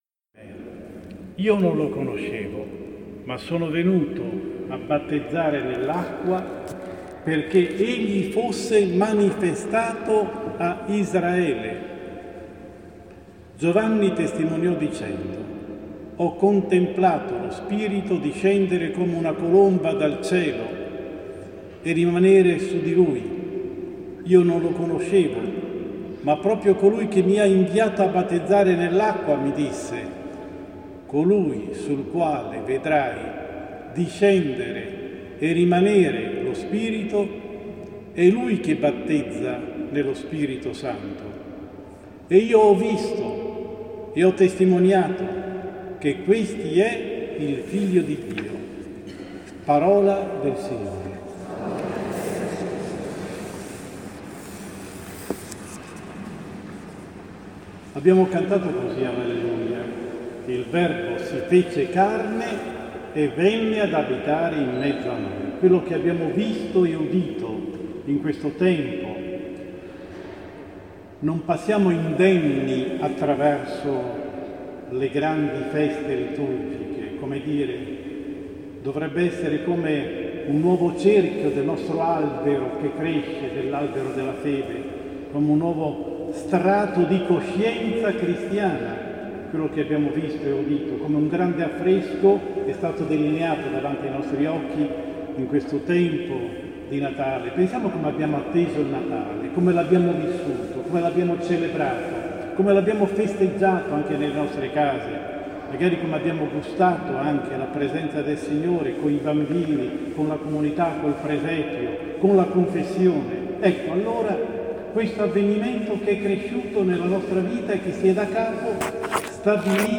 Omelia 15 gennaio 2017 – Seconda domenica Anno A